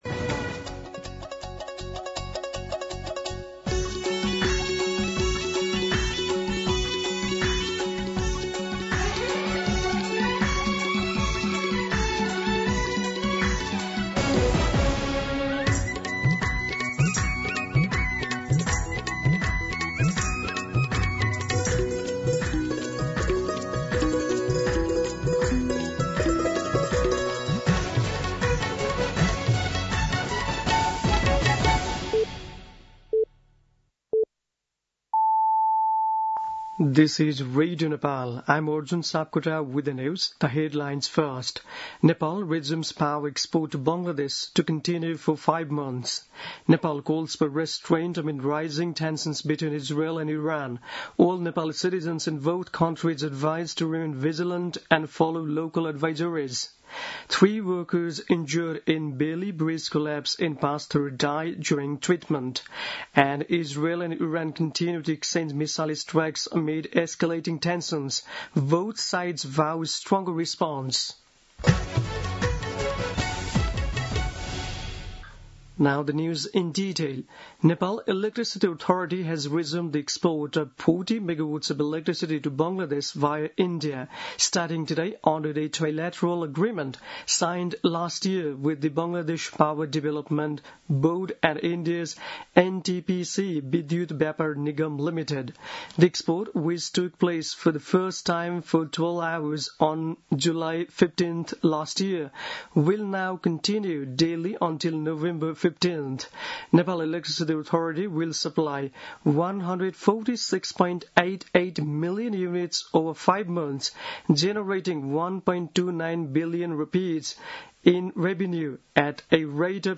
दिउँसो २ बजेको अङ्ग्रेजी समाचार : १ असार , २०८२
2pm-English-News-03-01.mp3